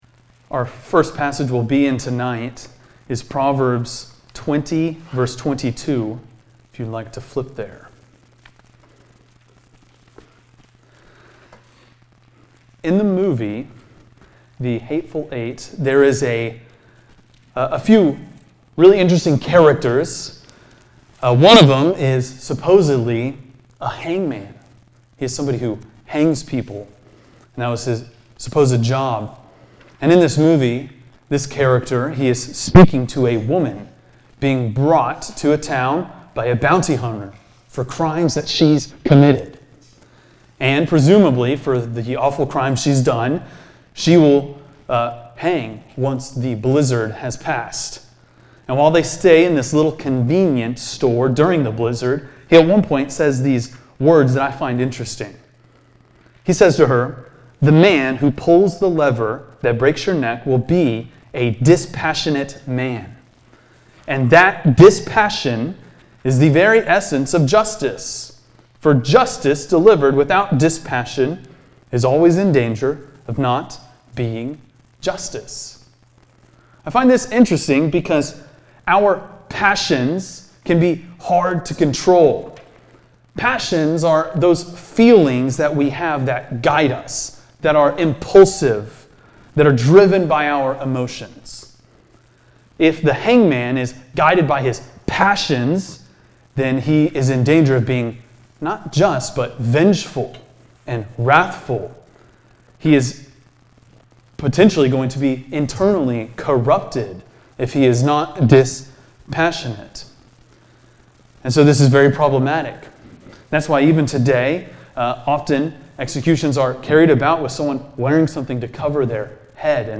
preaches on the topic of wrathfulness in the book of Proverbs.&nbsp